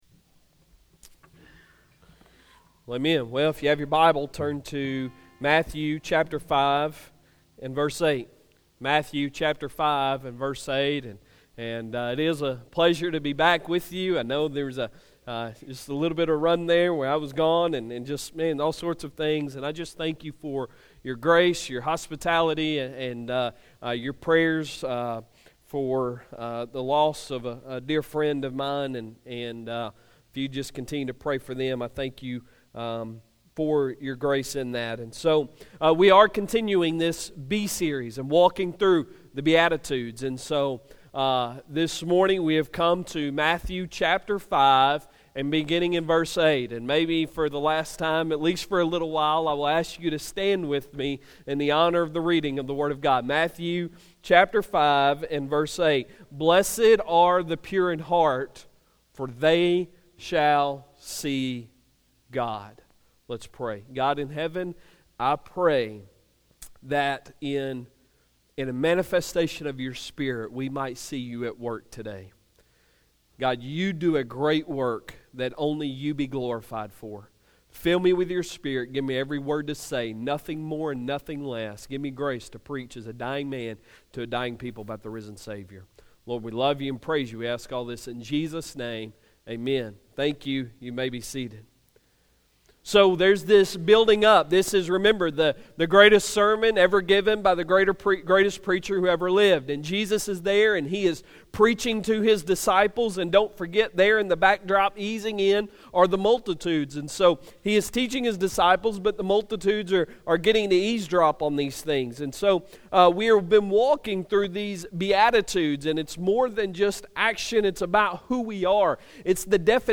Sunday Sermon November 4, 2018
Sermons